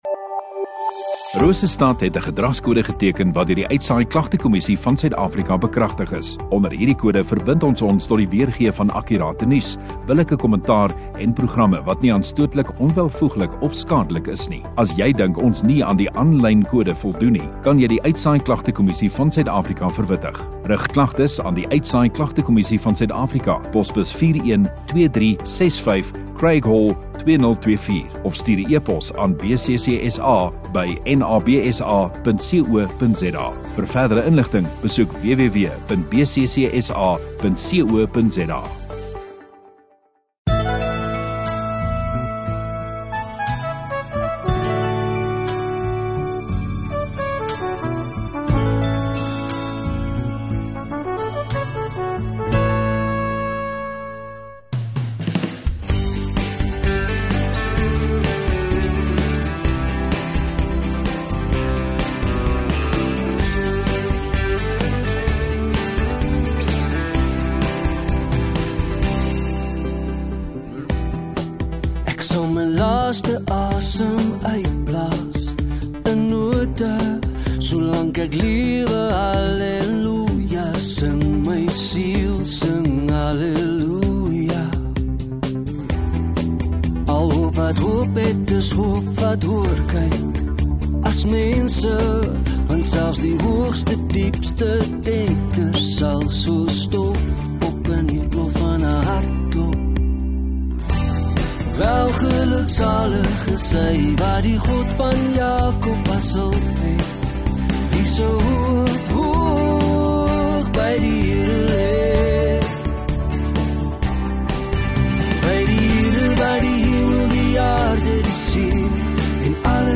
10 Jan Vrydag Oggenddiens